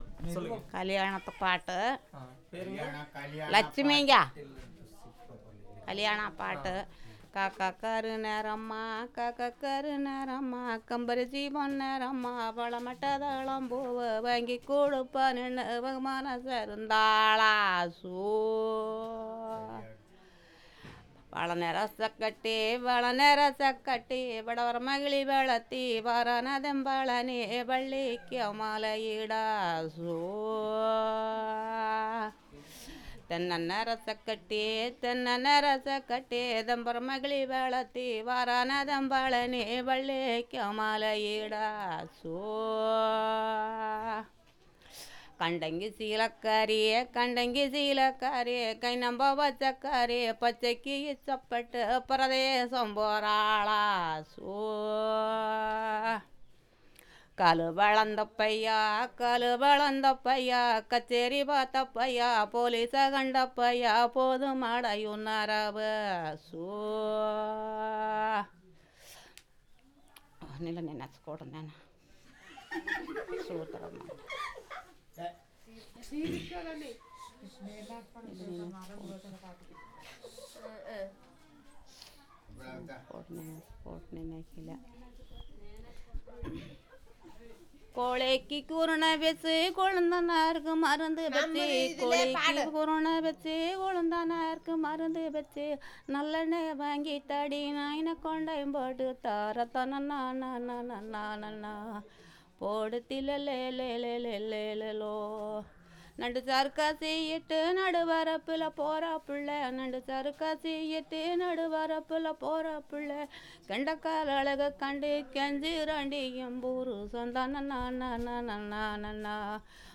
Performance of Traditional song